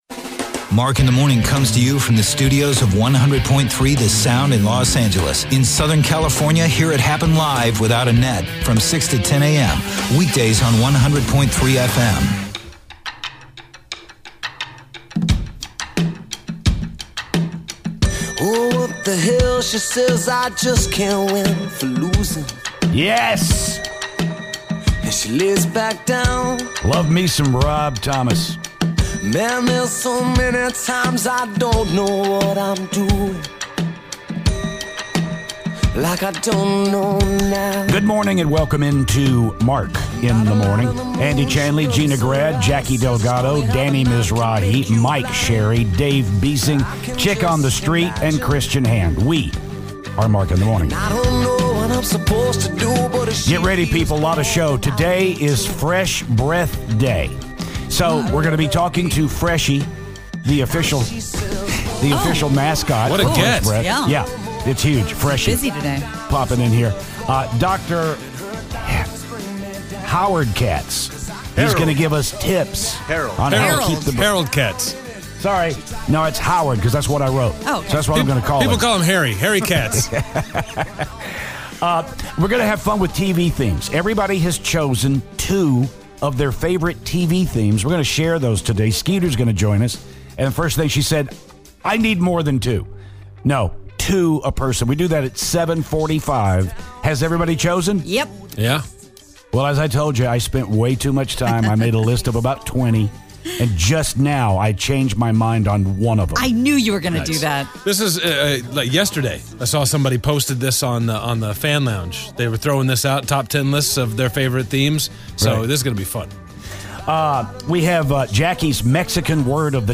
The crew picks their top 3 TV theme songs and Paul Stanley from KISS calls the show!